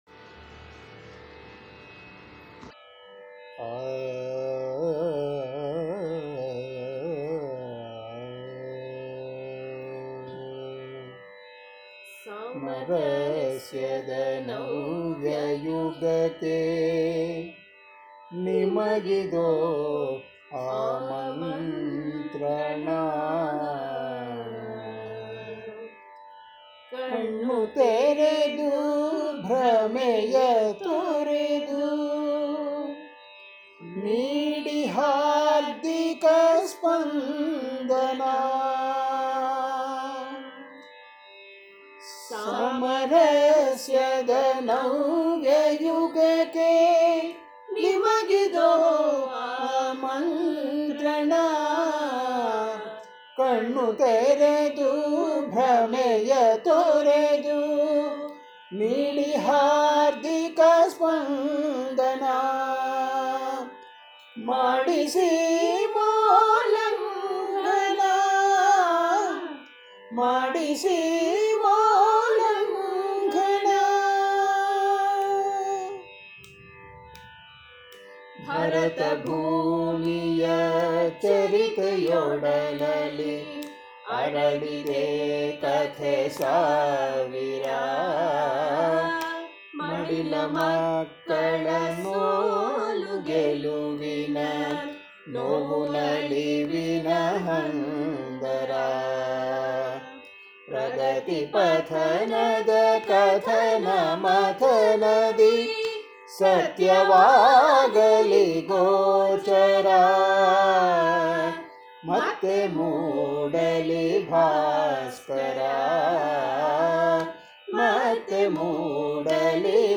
Patriotic Songs Collections
Solo